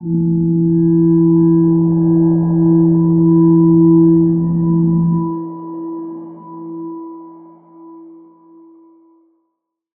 G_Crystal-E4-pp.wav